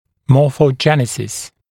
[ˌmɔːfə(u)ˈdʒɛnɪsɪs][ˌмо:фо(у)ˈджэнисис]морфогенез, формообразование
morphogenesis.mp3